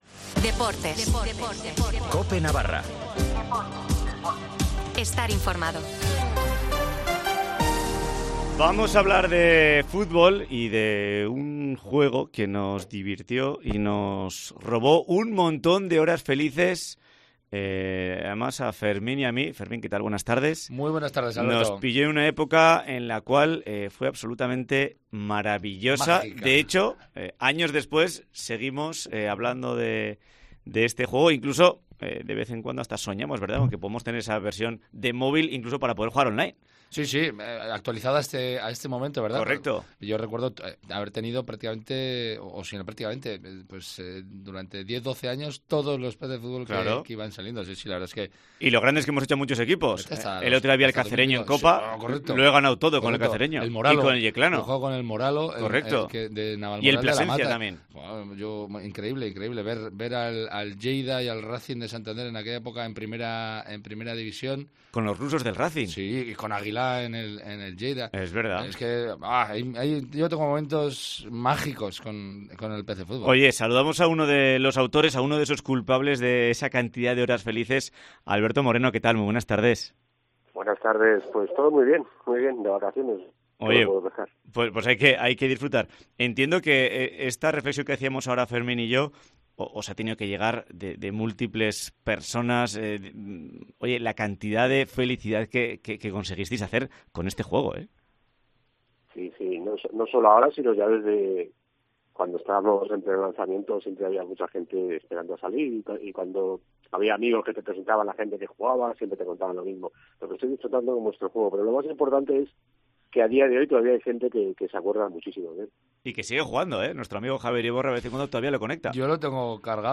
Hablamos con dos de los "culpables" de una saga que nos hizo felices muchas horas frente a un ordenador 00:00 Volumen Descargar PC Fútbol, el juego que nos hizo soñar.